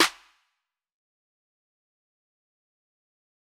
Snares
JJSnares (10).wav